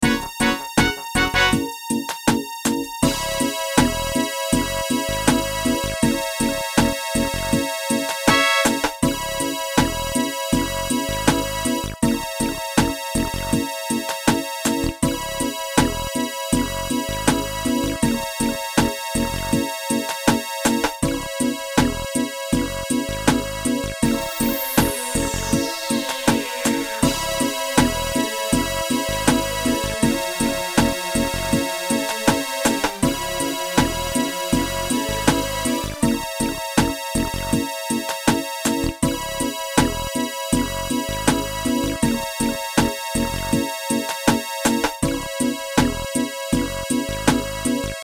テンポ80のゆったりとしたダンス着信音。
夢の中にいるような、幻想的なステップを踏むような、心地よいサウンドです。心身をリラックスさせ、安らぎを与えてくれます。